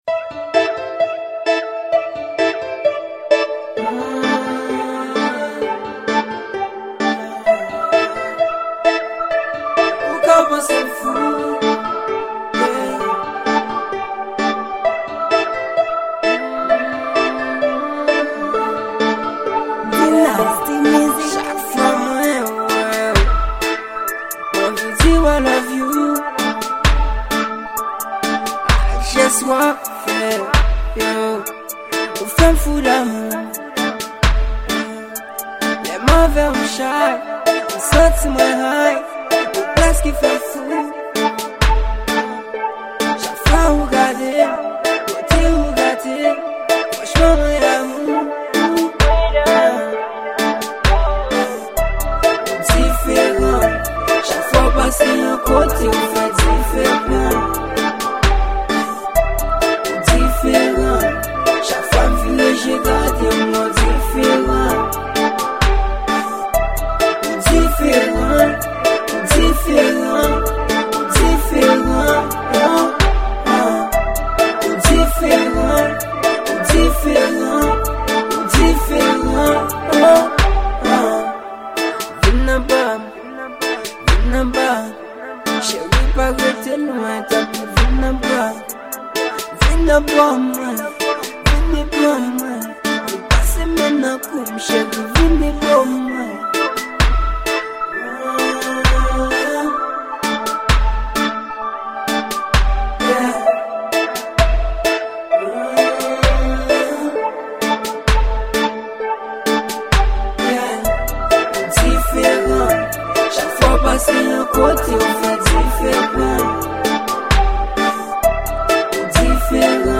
Genre: WORLD